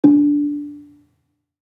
Gambang-D3-f.wav